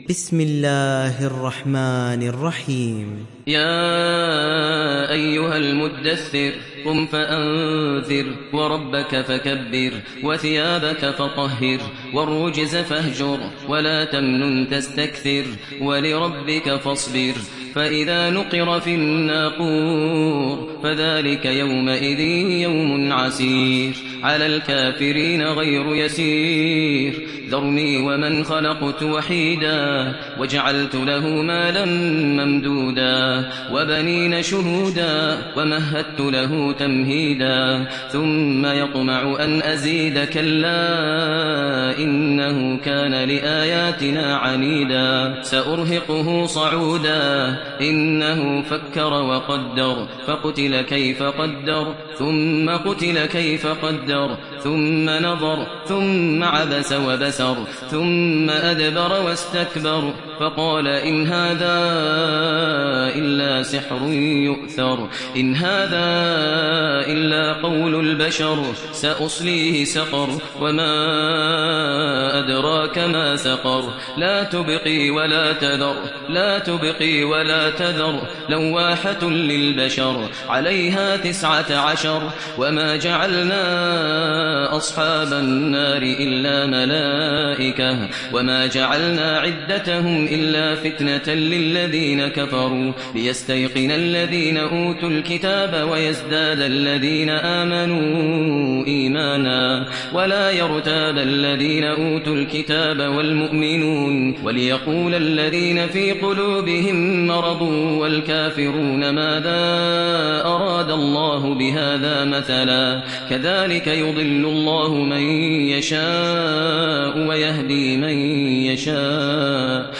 دانلود سوره المدثر mp3 ماهر المعيقلي روایت حفص از عاصم, قرآن را دانلود کنید و گوش کن mp3 ، لینک مستقیم کامل